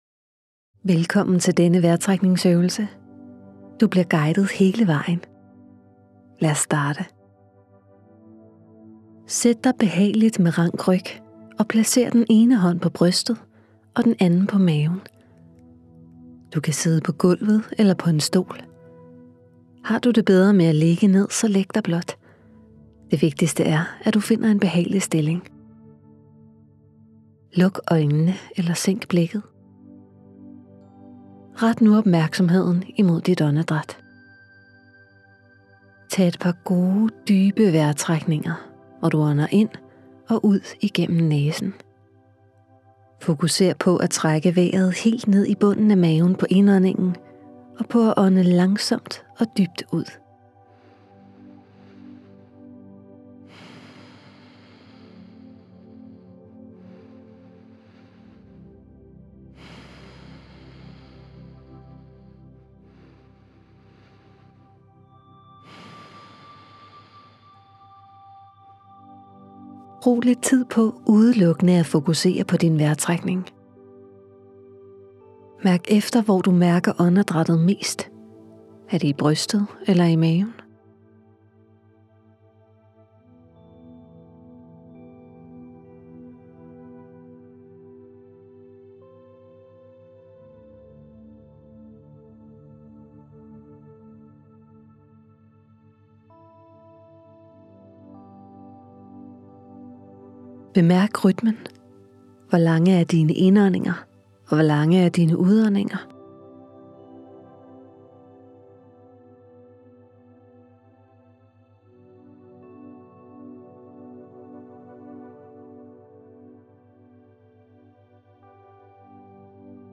Det bevidste åndedræt – vejrtrækningsøvelse med lydguide
• Du starter med at få en intro til vejrtrækningsøvelsen.
• Du får hjælp til at holde fokus hele vejen igennem.